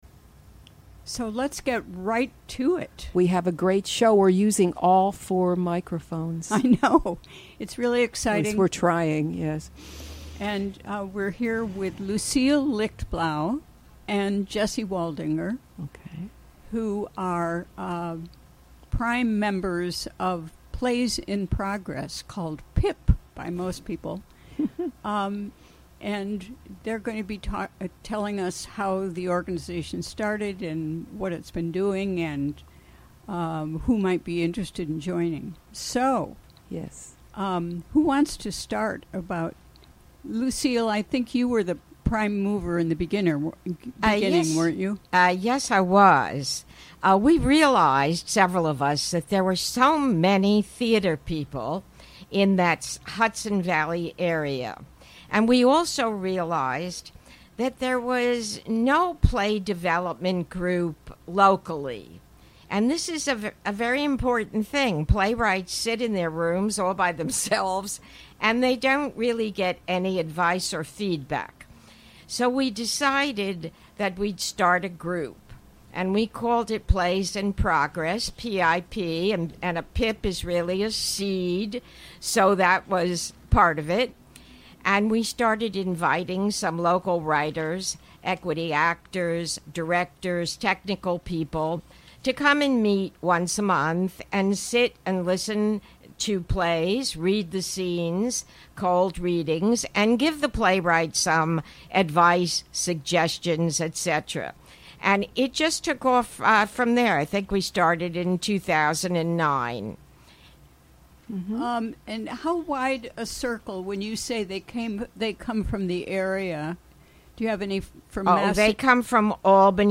Recorded during the WGXC Afternoon Show on August 24, 2017.